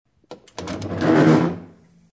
Звуки стула
Огромный стул передвигают по полу